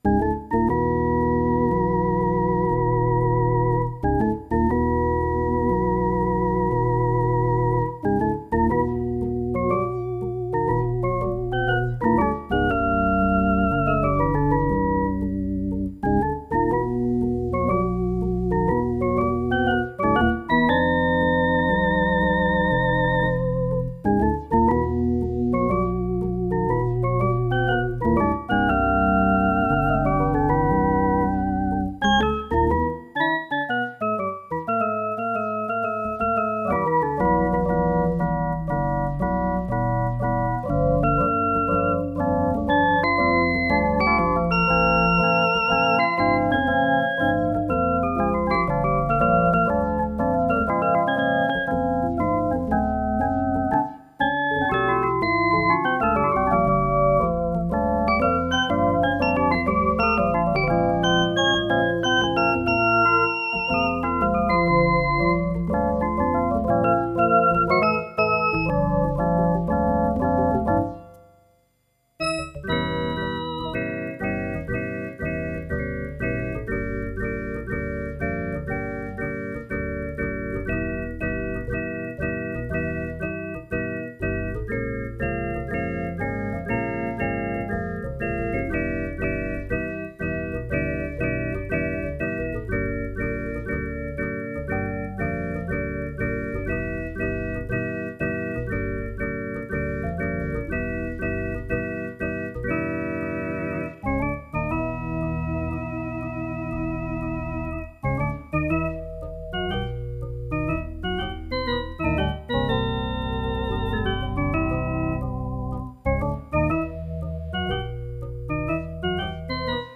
Clip made using two different organ presets